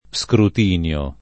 Skrut&nLo] s. m.; pl.